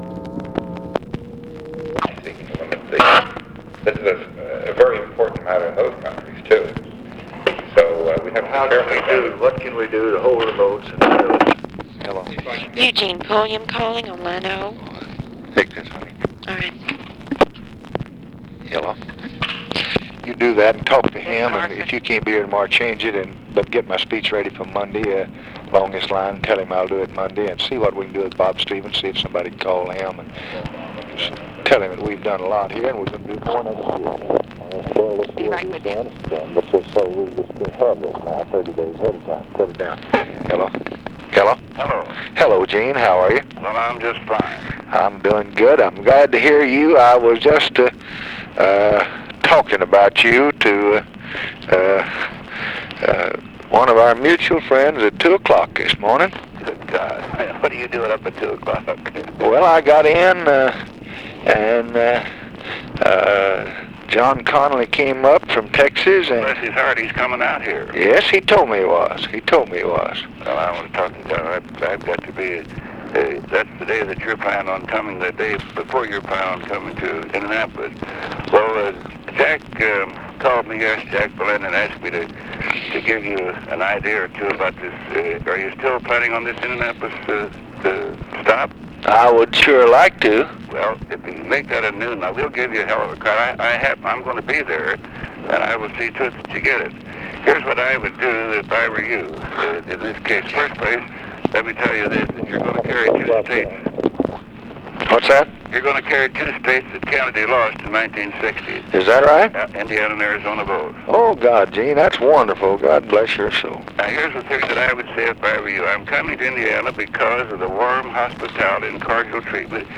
Conversation with EUGENE PULLIAM, OFFICE CONVERSATION, JACK VALENTI and JOHN CONNALLY, September 23, 1964
Secret White House Tapes